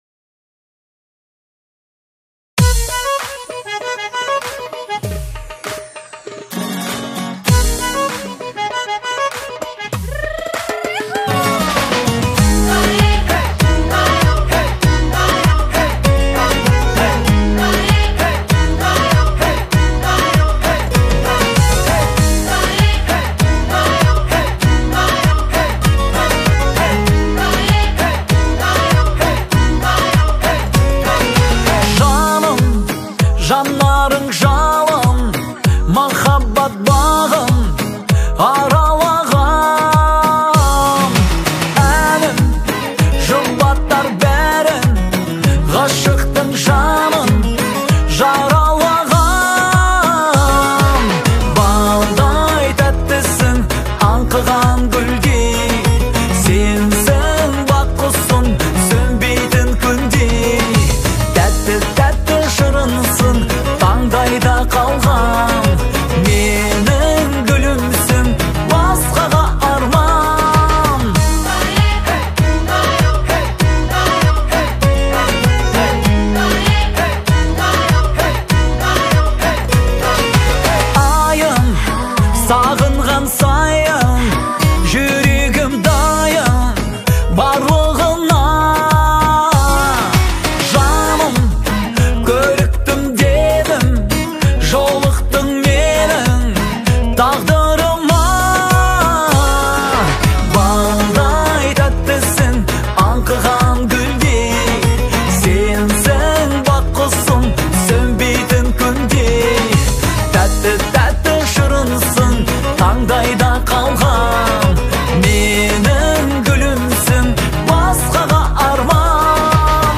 это яркая и мелодичная песня в жанре казахской поп-музыки